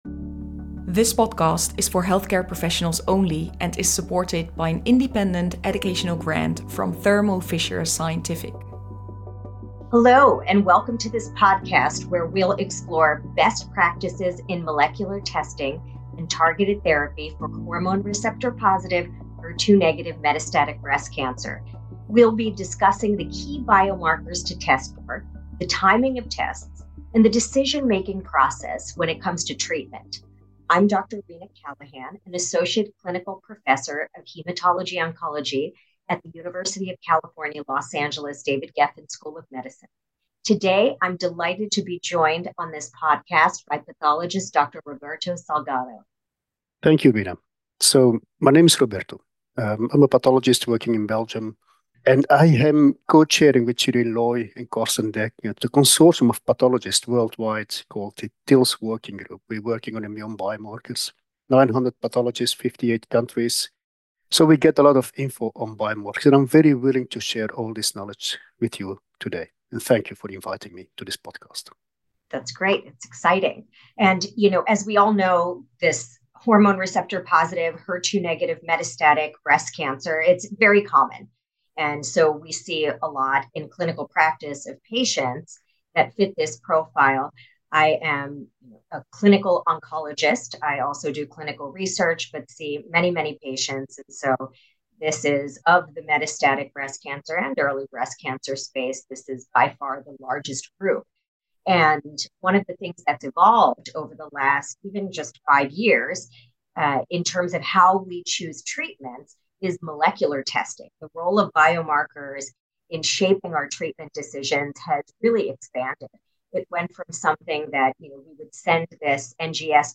Tune in for a practical, expert-led discussion on how molecular testing can guide personalised treatment and improve patient outcomes.